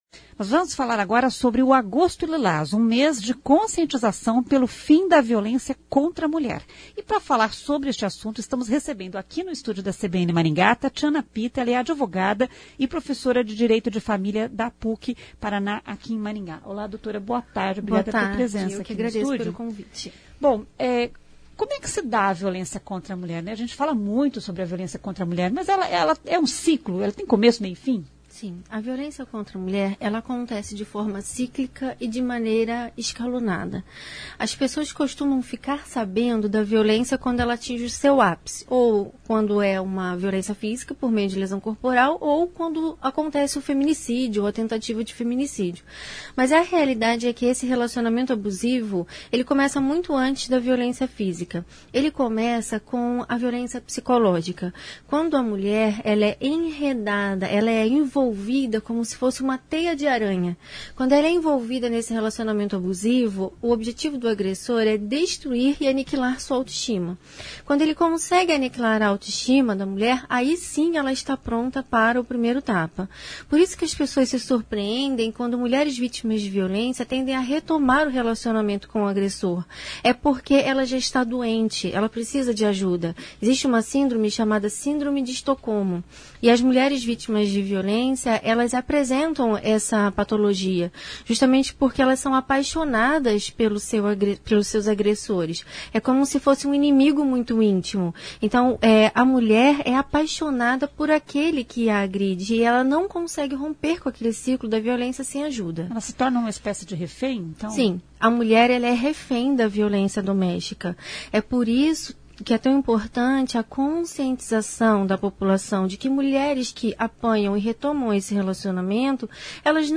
A CBN pede desculpas pela interpretação equivocada da fala da entrevistada.